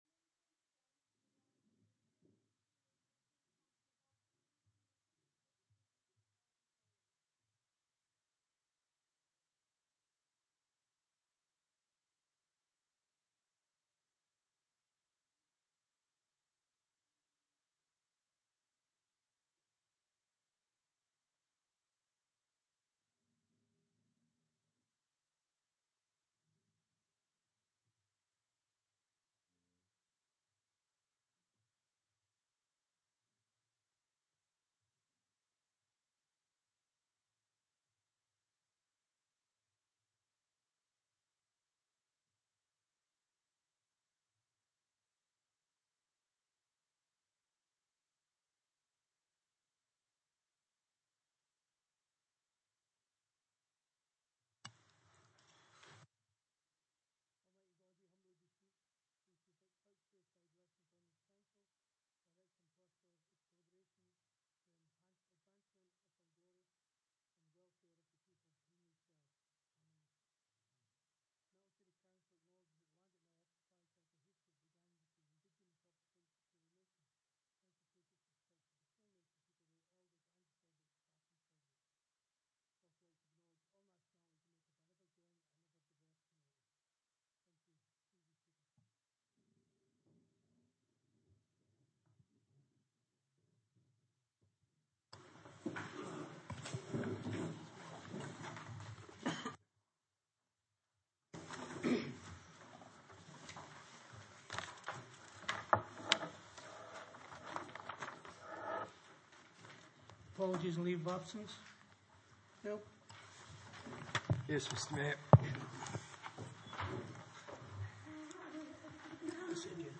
Ordinary Meeting 22 July 2019
Burnside Community Hall, 23 Lexington Drive, Burnside View Map